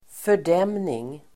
Uttal: [för_d'em:ning]